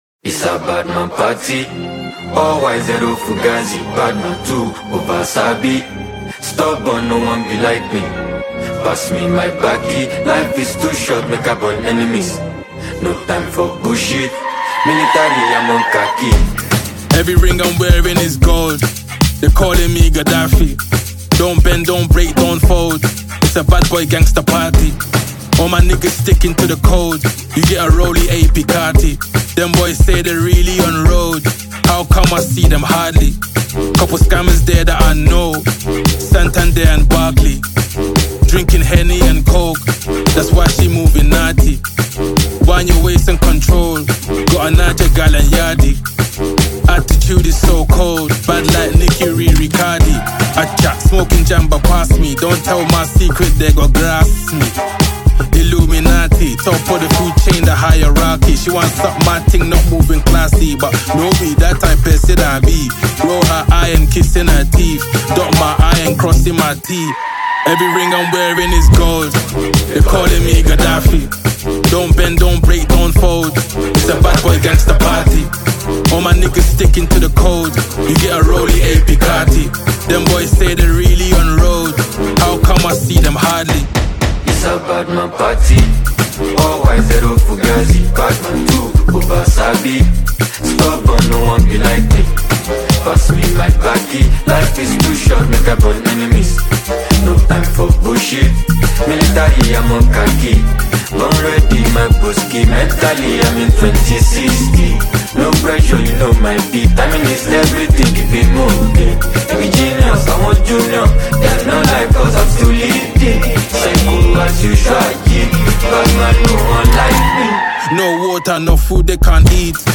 American rapper and talented artist